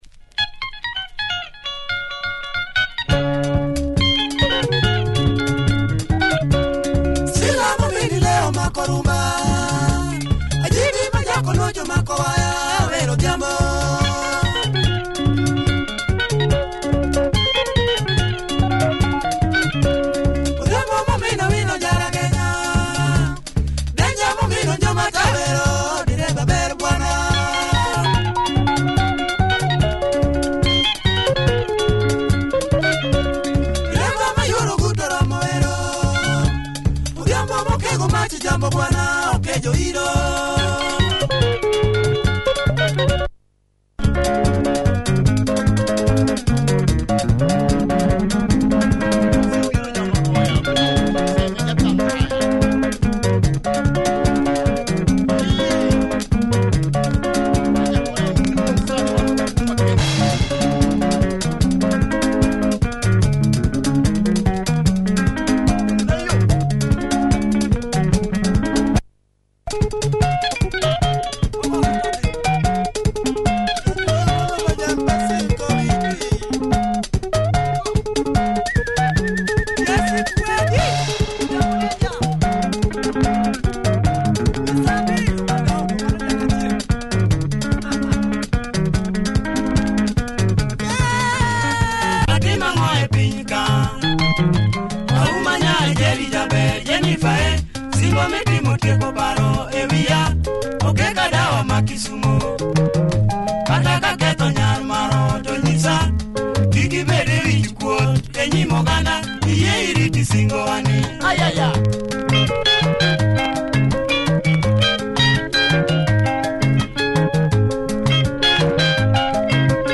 Tight Luo Benga